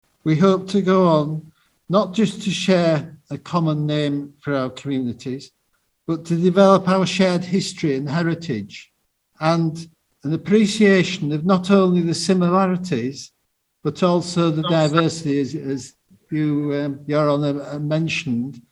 Selby, Ontario and Selby in the United Kingdom are now officially twinned following a special meeting featuring the two Selby’s councils.
Selby, UK Mayor Michael Dyson said this signing was the laying of the foundation stone of friendship and cultural links already established.